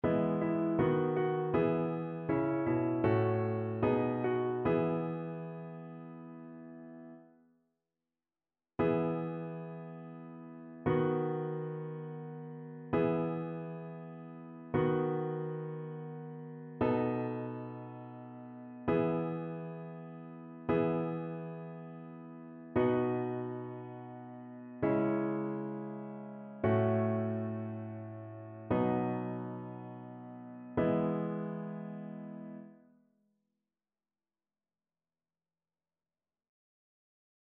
annee-b-temps-ordinaire-19e-dimanche-psaume-33-satb.mp3